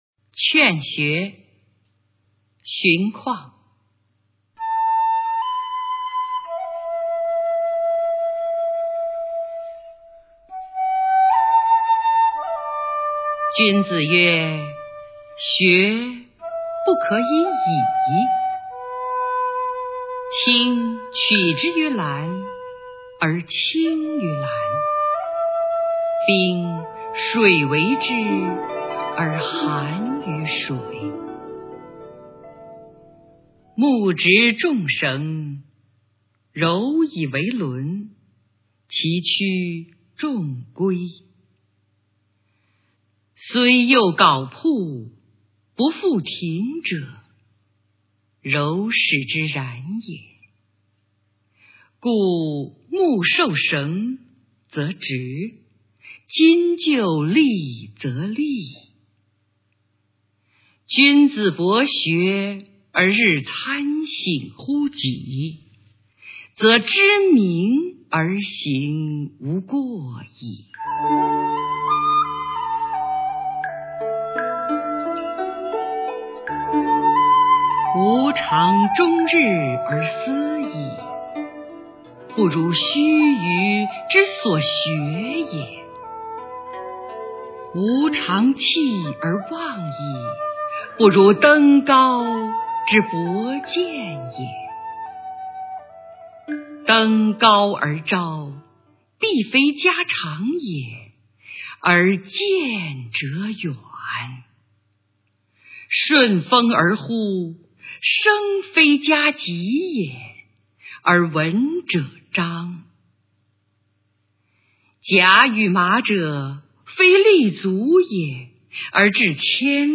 首页 视听 语文教材文言诗文翻译与朗诵 高中语文必修三
荀况《劝学》原文和译文（含朗读）